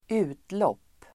Uttal: [²'u:tlåp:]
utlopp.mp3